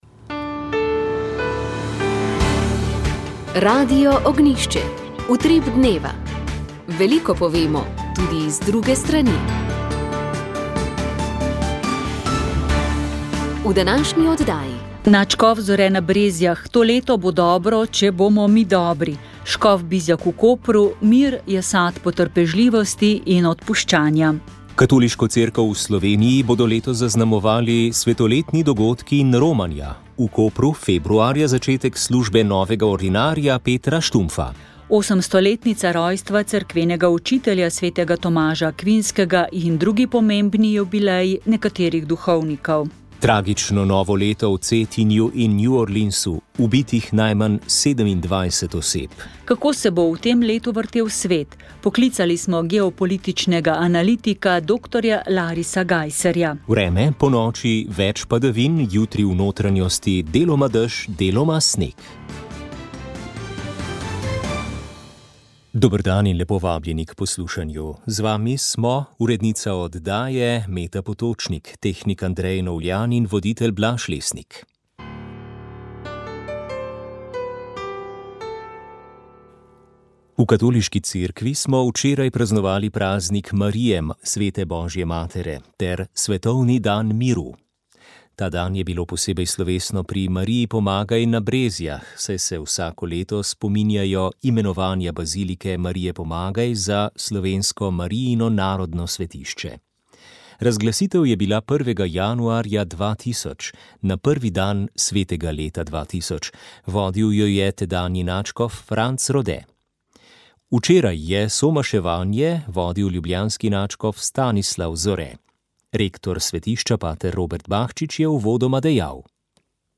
Radio Ognjišče info novice Informativne oddaje VEČ ...